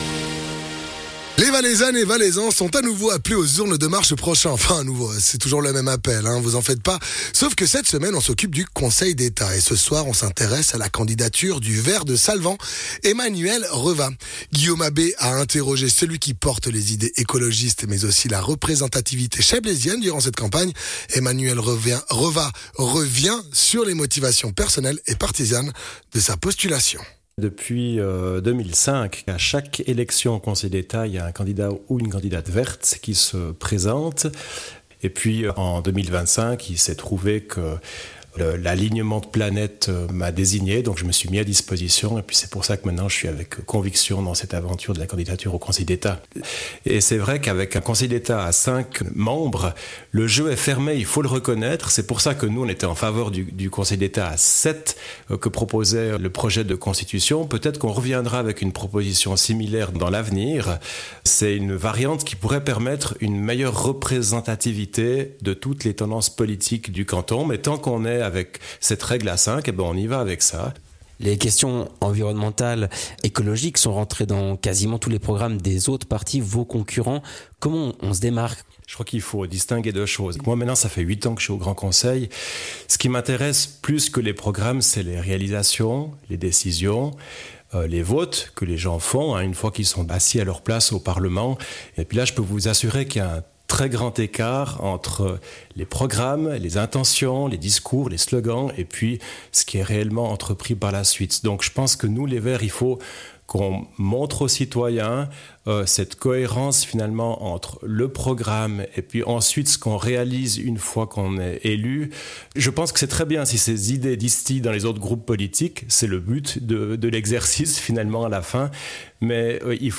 Intervenant(e) : Emmanuel Revaz - candidat des Verts valaisans